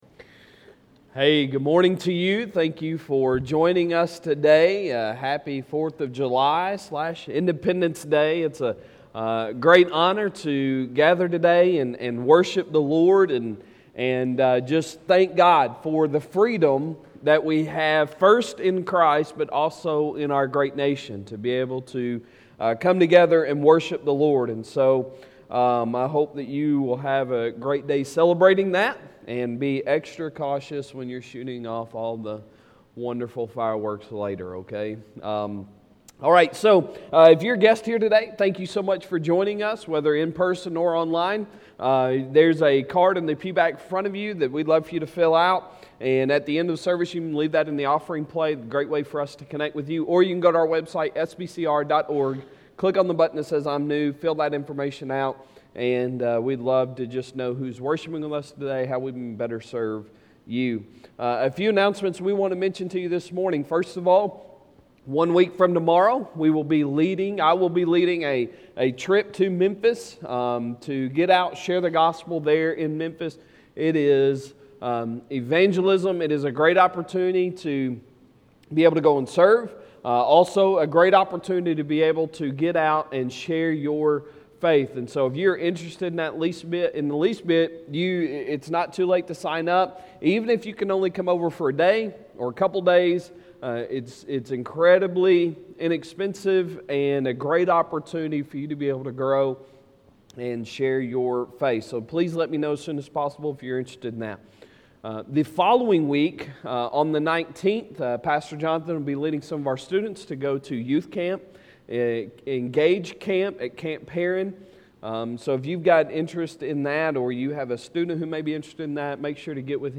Sunday Sermon July 4,2021